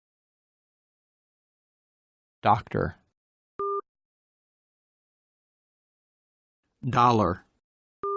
Âm /d/
•  Âm /d/ âm họng: Dây thanh âm đóng, hơi từ họng bật thoát ra làm bật đầu lưỡi tạo âm “đờ”.
am-d-doctor-dolar.mp3